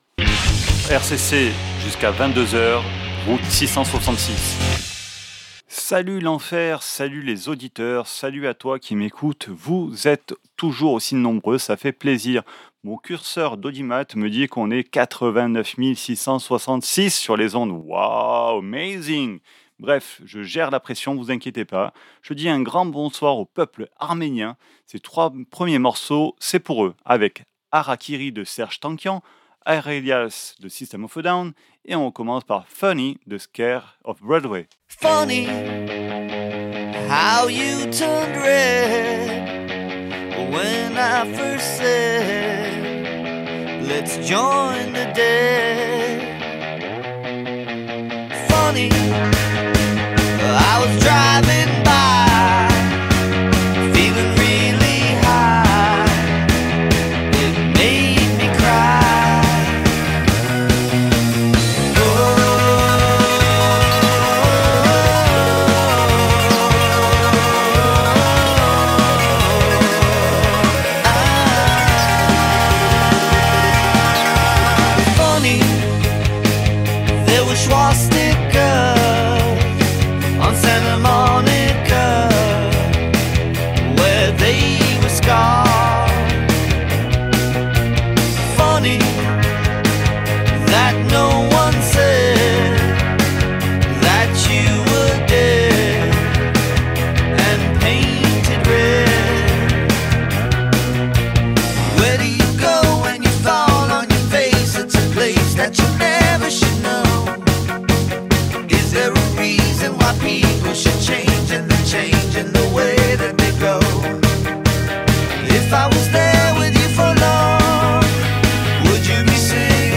Route 666 Emission du mercredi 28 JANVIER 2026 votre dose de hard rock métal sur RCC !